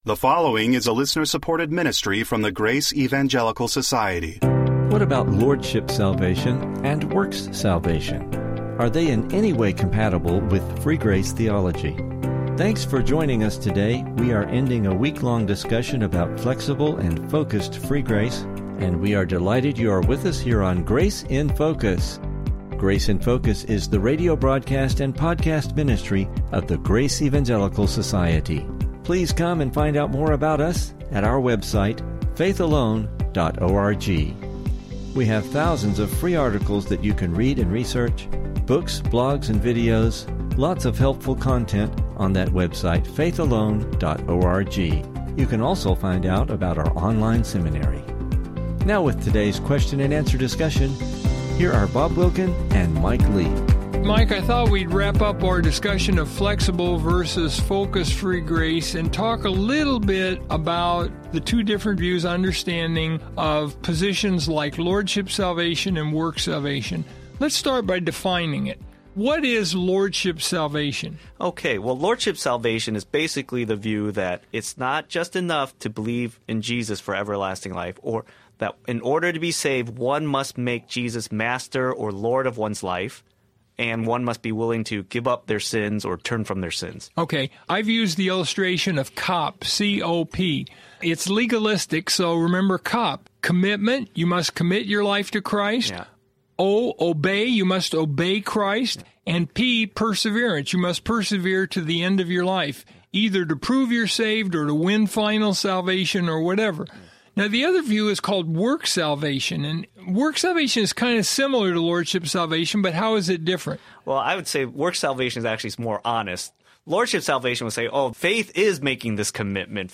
What do these look like? Please listen for an interesting Biblical discussion regarding this subject!